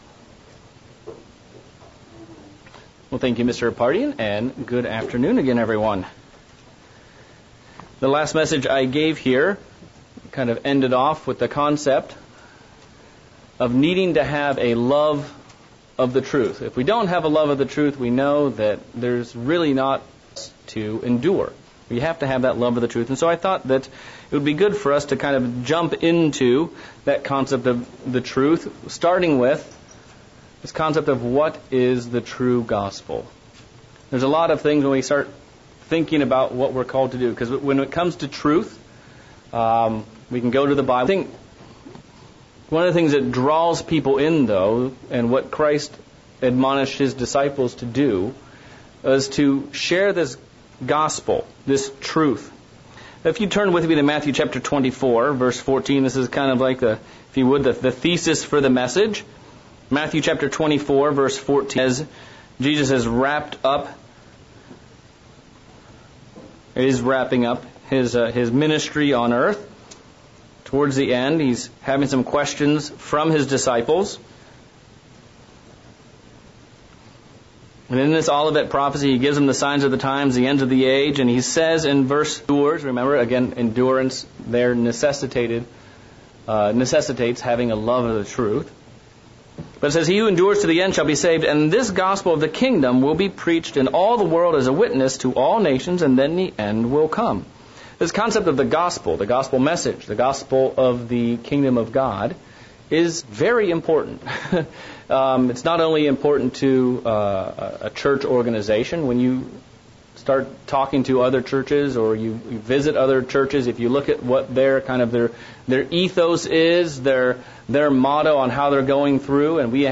Sermon looking at what exactly is the Gospel of the Kingdom of God? What is the true gospel Jesus taught?